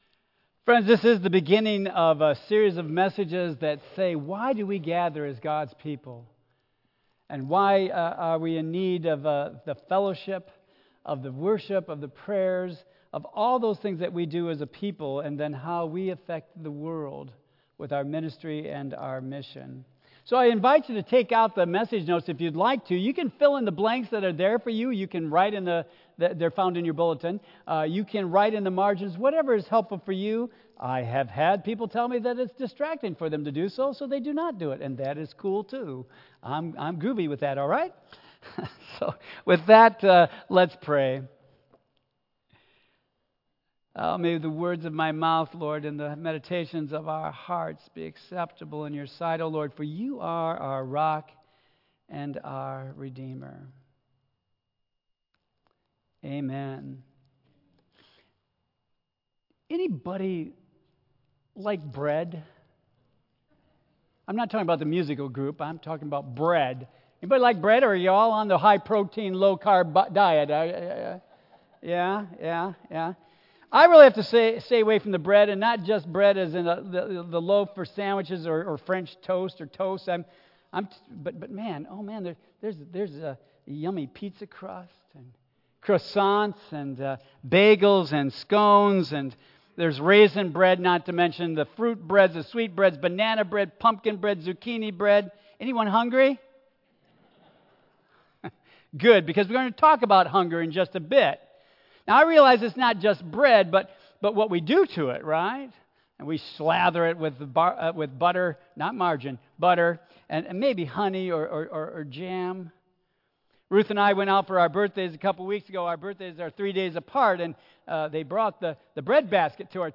Tagged with Michigan , Sermon , Waterford Central United Methodist Church , Worship Audio (MP3) 8 MB Previous When Is It "A God Thing"?